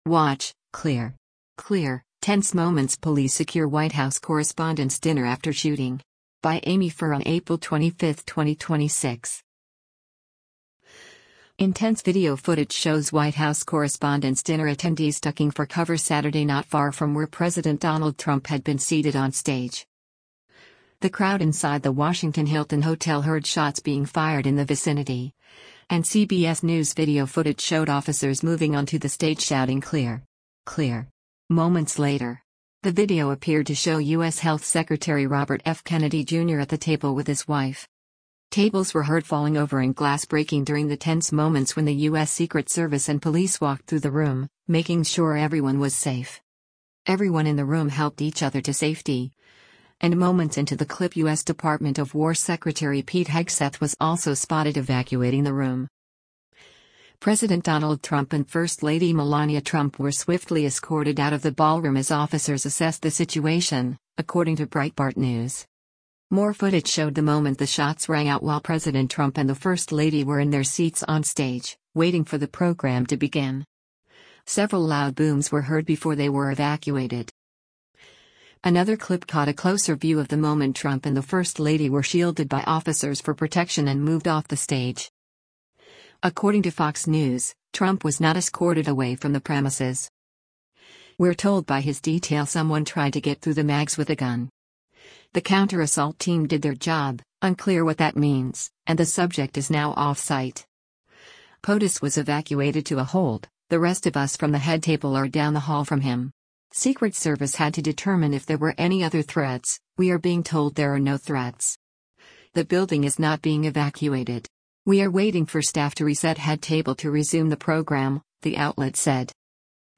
Intense video footage shows White House Correspondents Dinner attendees ducking for cover Saturday not far from where President Donald Trump had been seated onstage.
The crowd inside the Washington Hilton hotel heard shots being fired in the vicinity, and CBS News video footage showed officers moving onto the stage shouting “Clear! Clear!” moments later.
Tables were heard falling over and glass breaking during the tense moments when the U.S. Secret Service and police walked through the room, making sure everyone was safe.